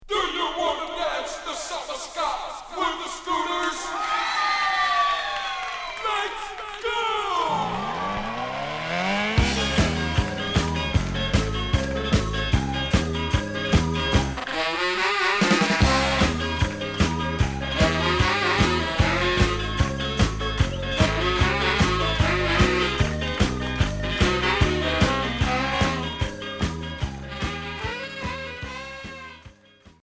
Ska Unique 45t retour à l'accueil